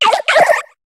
Cri de Grenousse dans Pokémon HOME.